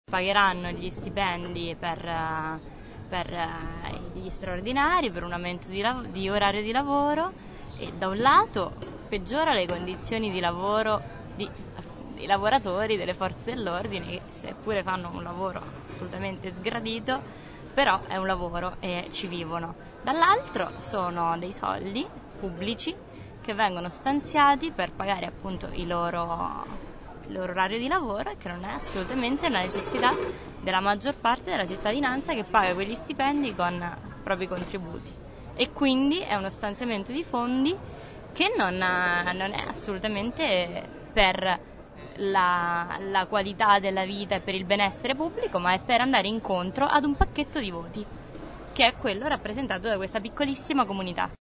Da un giro in piazza, registratore alla mano, e due chiacchiere con chi la piazza la vive ma anche con chi in piazza ci vive, sono emersi i diversi punti di vista che alimentano il dibattito.
studentessa